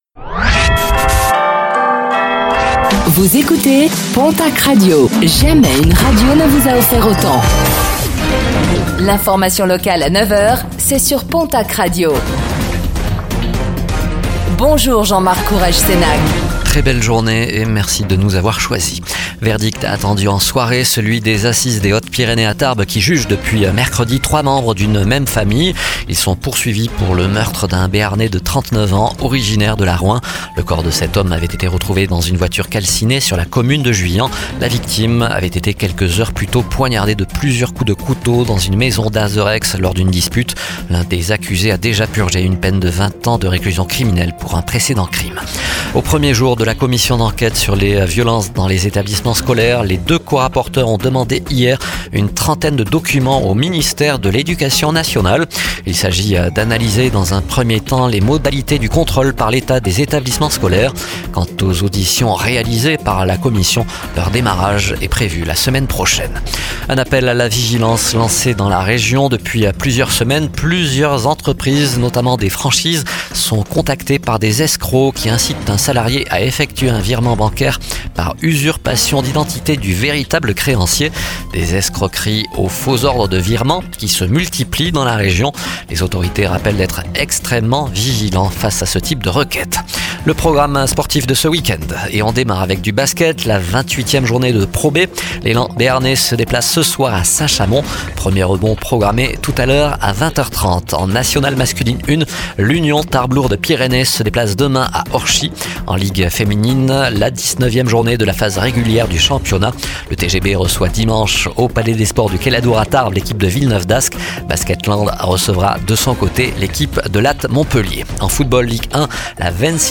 Réécoutez le flash d'information locale de ce vendredi 14 mars 2025